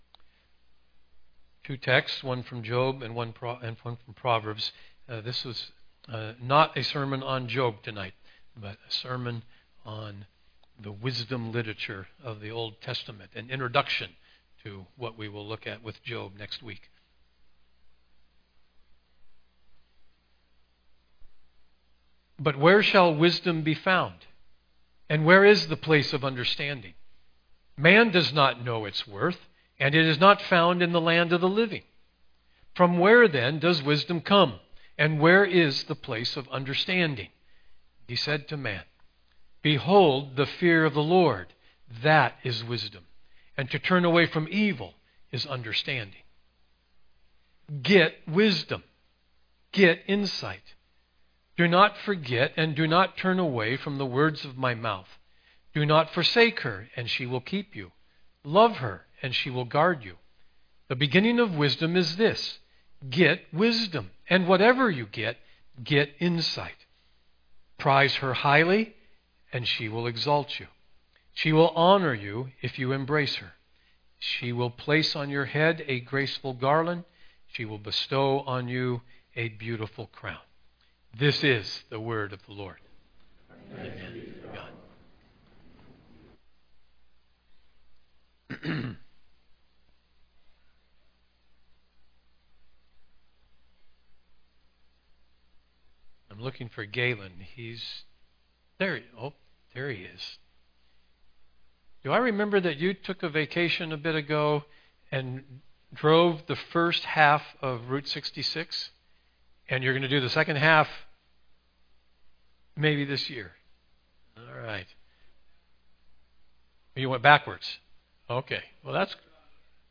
Download Sermon Notes Listen & Download Audio Preacher